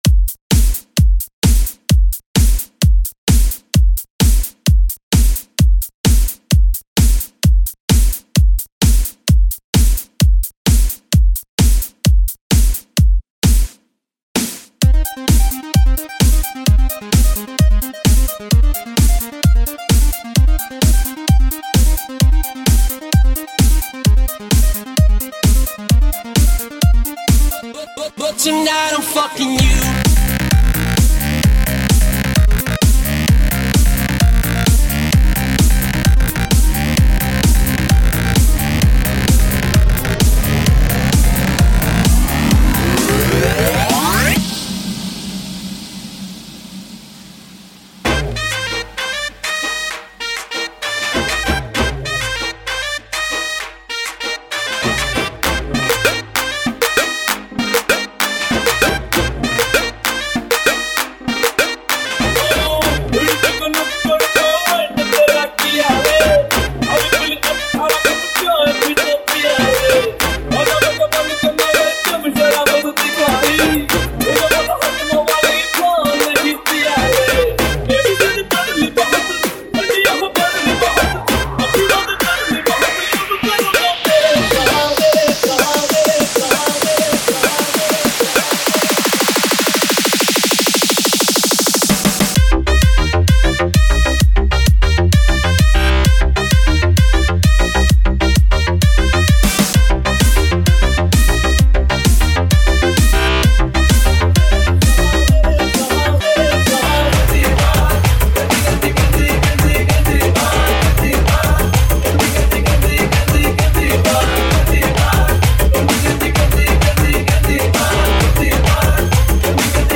Latest DJ-Mixes